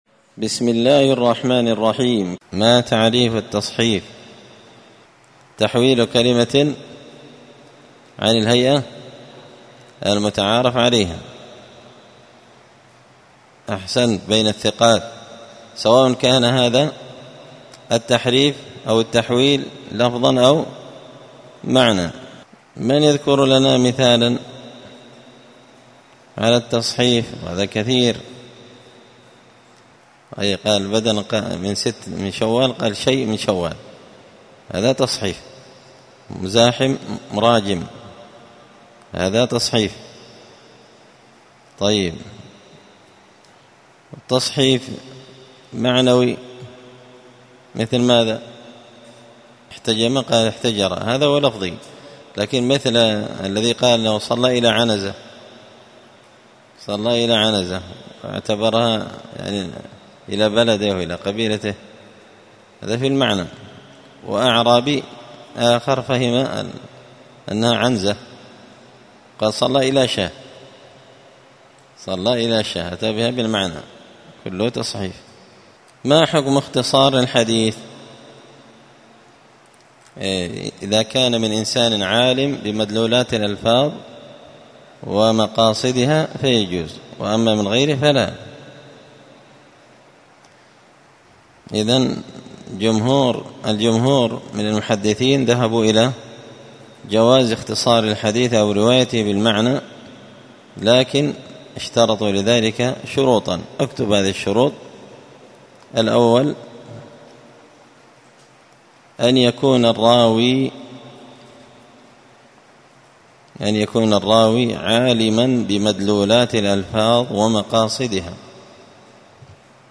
تعليق وتدريس الشيخ الفاضل:
21الدرس-الحادي-والعشرون-من-كتاب-نزهة-النظر-للحافظ-ابن-حجر.mp3